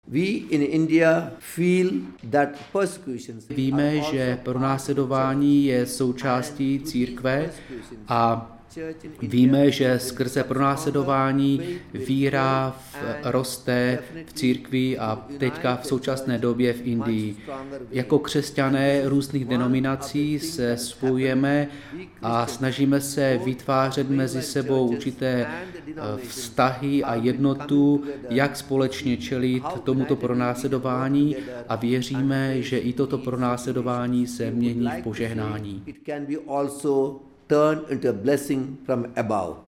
Exkluzivní rozhovor
řekl Proglasu arcibiskup z Bangalore Bernard Moras (audio MP3)řekl Proglasu arcibiskup z Bangalore Bernard Moras.